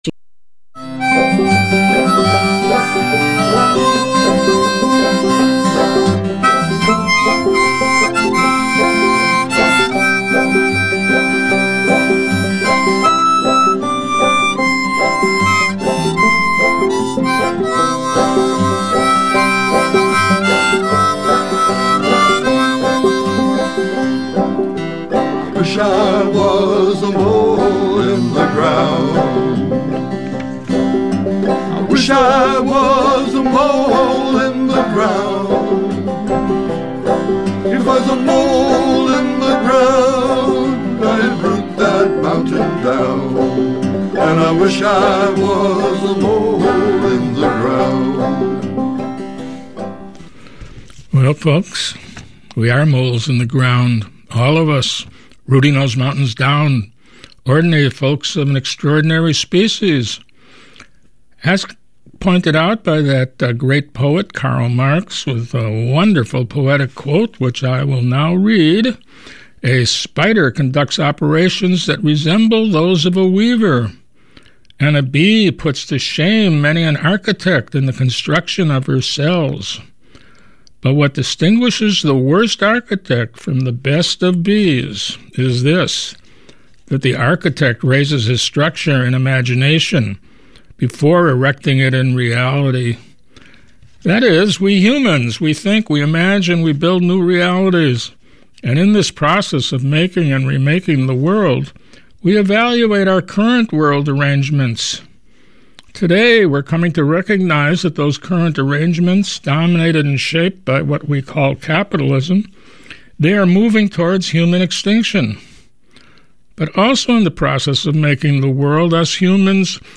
Interviews and Commentary